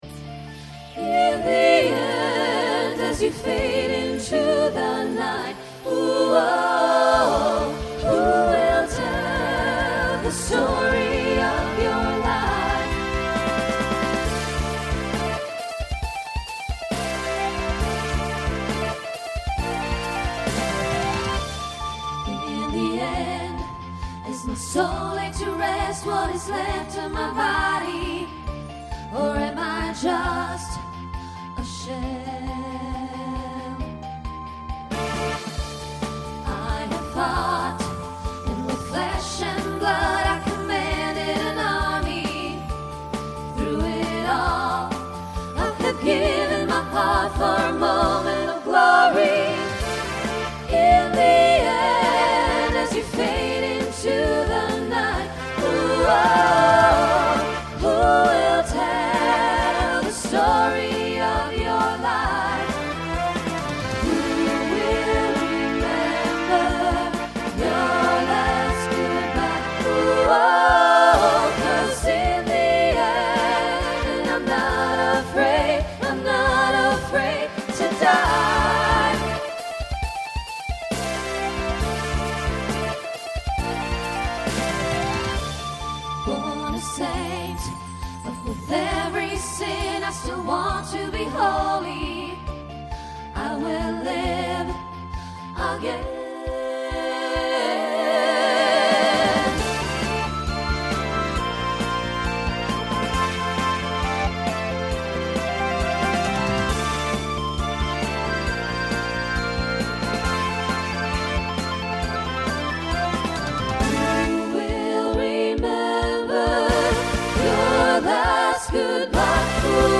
SATB Instrumental combo Genre Rock